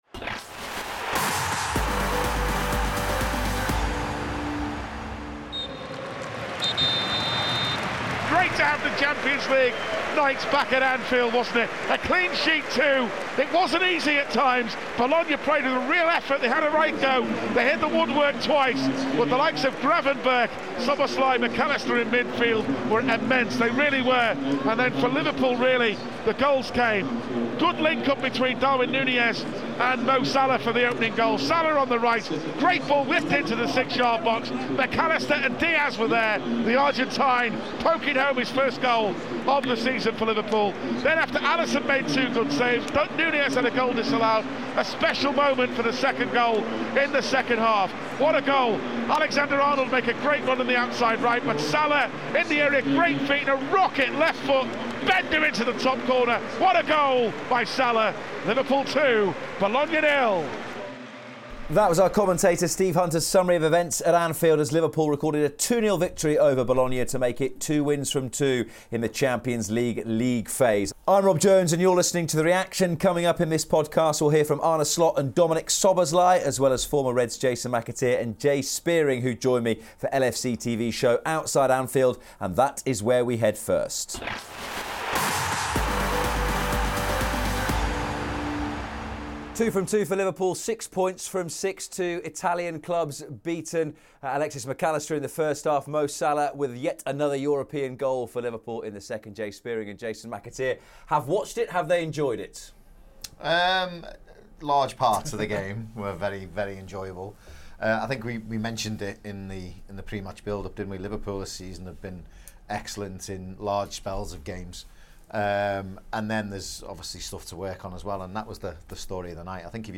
In this episode of The Reaction, Arne Slot and Dominik Szoboszlai reflect on the win at Anfield,
LFCTV’s post-match show